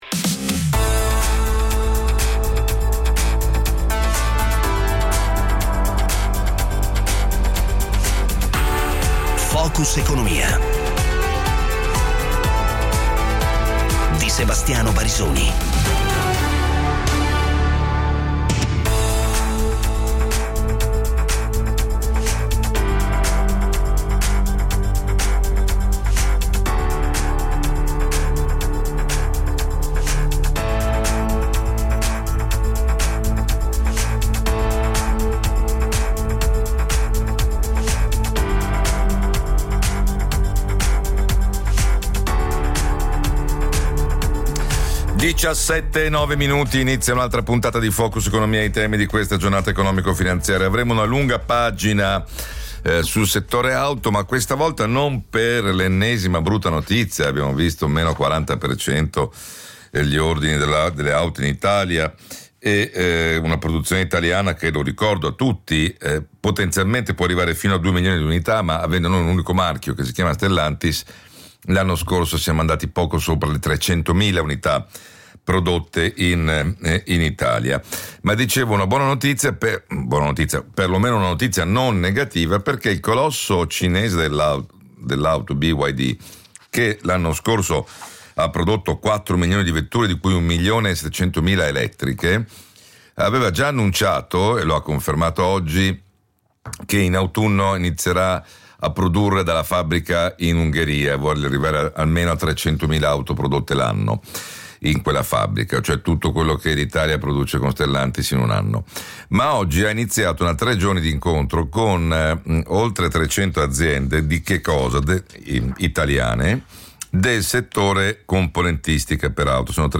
Focus economia è il programma quotidiano dedicato all'approfondimento dei temi dell'attualità dell'economia e della finanza, realizzato con i protagonisti della giornata economico finanziaria e il contributo di giornalisti e analisti de Il Sole 24 ORE.